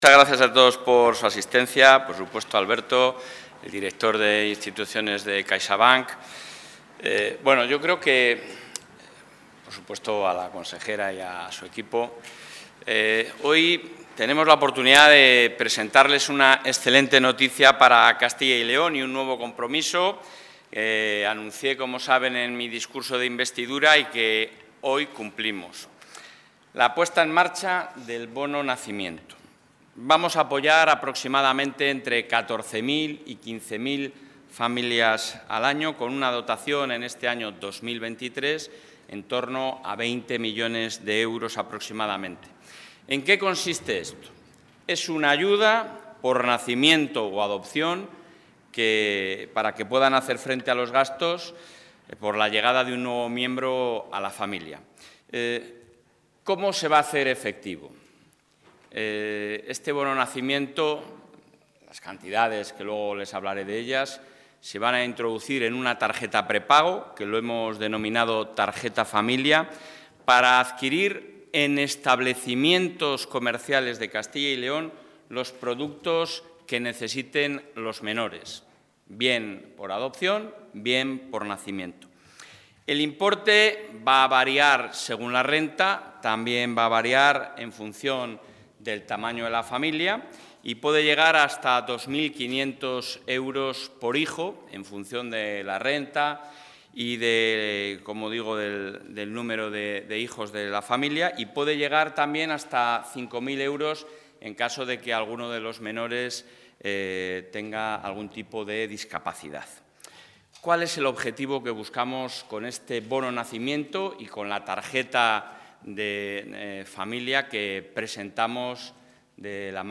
Intervención del presidente de la Junta.